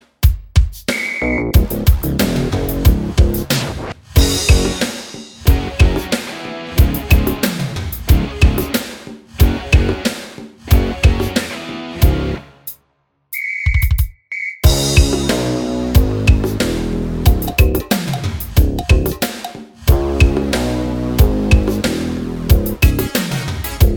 Minus Lead Guitar Pop (1990s) 3:54 Buy £1.50